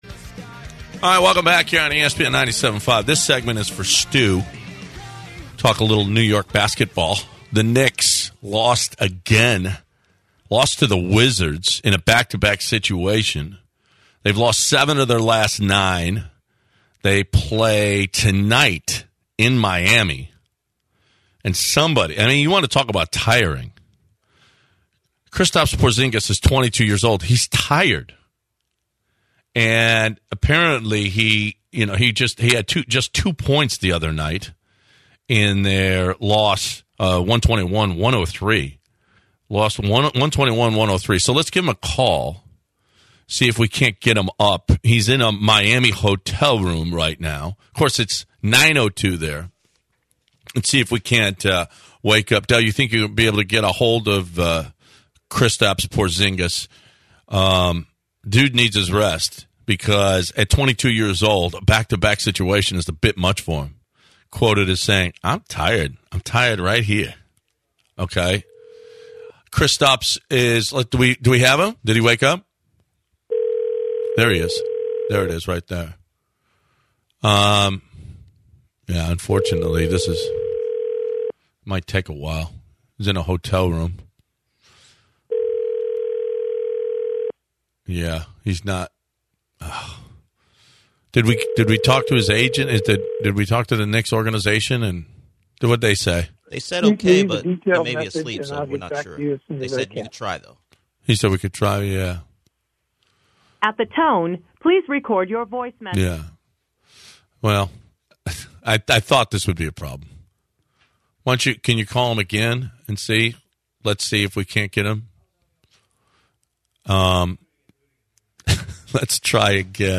An extremely exhausted and sleepy “Kristaps Porzingis” joins the Bench.